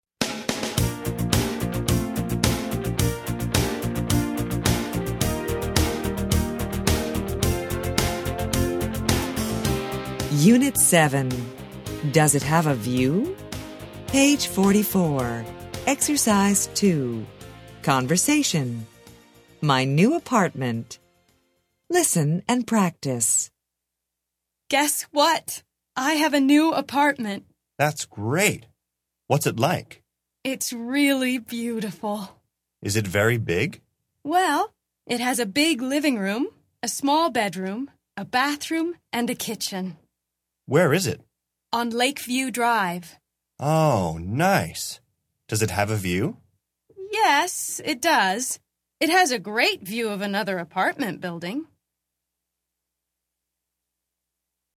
Interchange Third Edition Intro Unit 7 Ex 2 Conversation Track 19 Students Book Student Arcade Self Study Audio
interchange3-intro-unit7-ex2-conversation-track19-students-book-student-arcade-self-study-audio.mp3